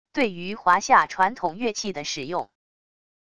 对于华夏传统乐器的使用wav音频